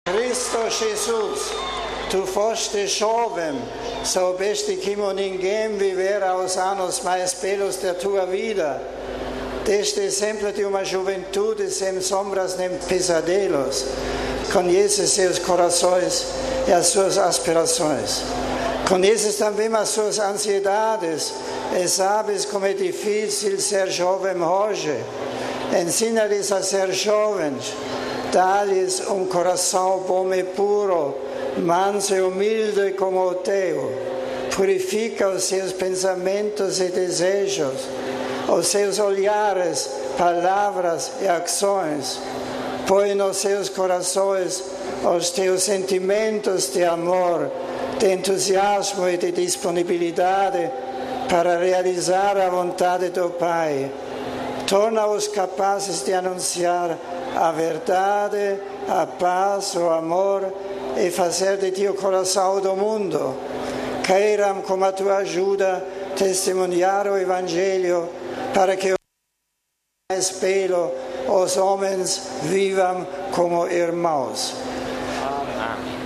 Luanda, 21 mar (RV) - Ao final de seu encontro com os jovens angolanos, no Estádio dos Coqueiros, em Luanda, o Santo Padre dirigiu uma comovente oração a Jesus, pedindo pelos jovens.